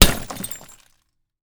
HammerHit03.wav